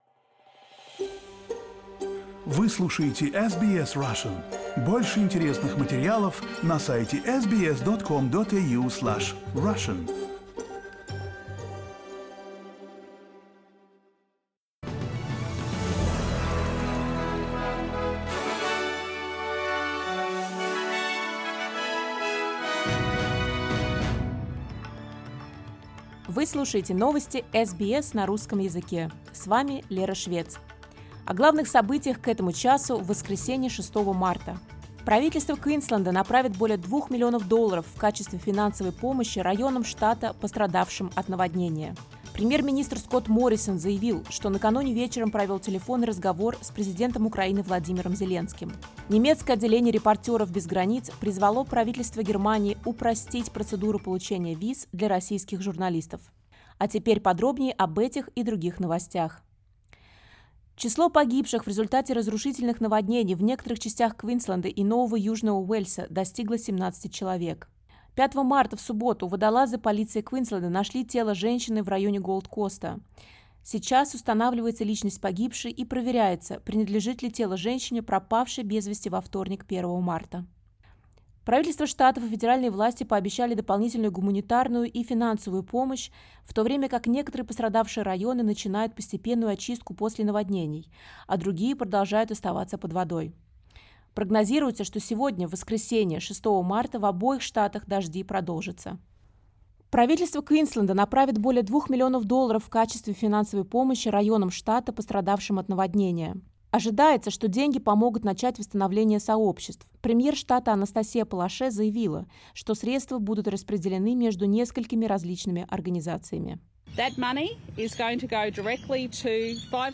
SBS news in Russian — 06.03